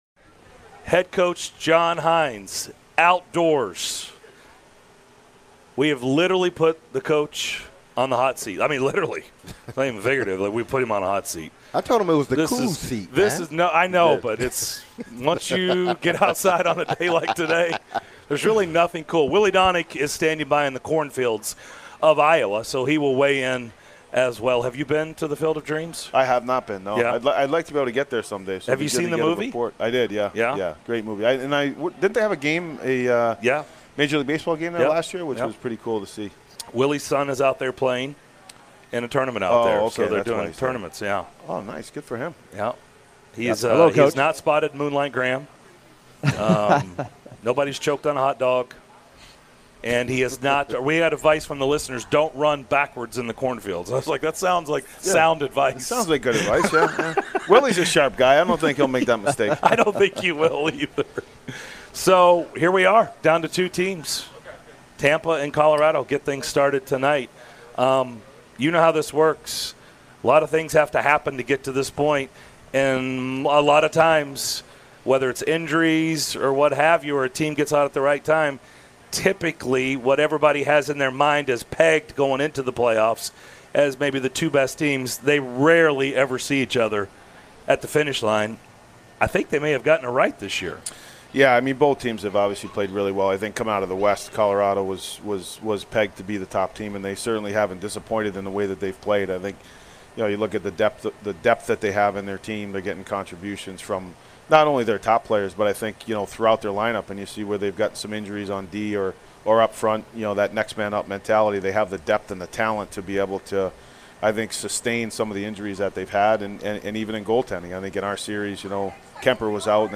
Nashville Predators Head Coach John Hynes joins the guys for 615 Day at Ford Ice Center in Antioch to talk about the 2021-22 season.